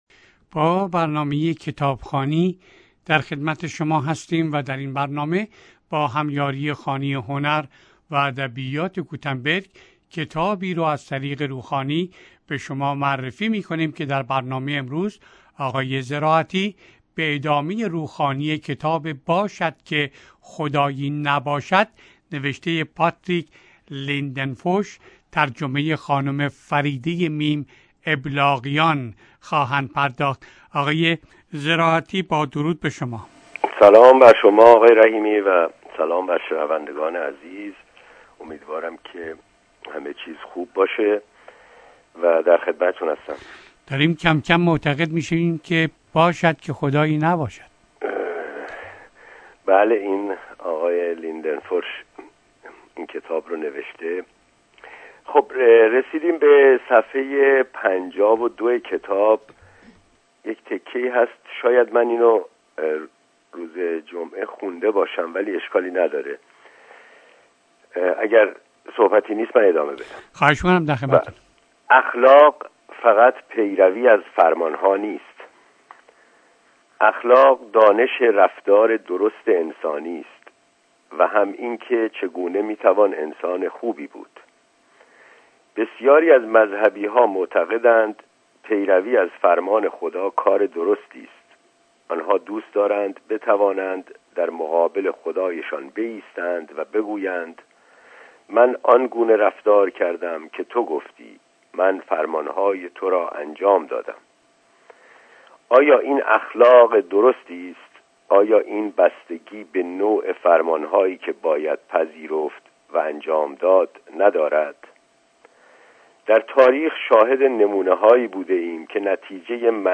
در برنامه کتابخوانی رادیو سپهر روخوانی شده و به یادگار در وبسایت رادیو پیام قرار می گیرد.